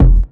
GS Phat Kicks 015.wav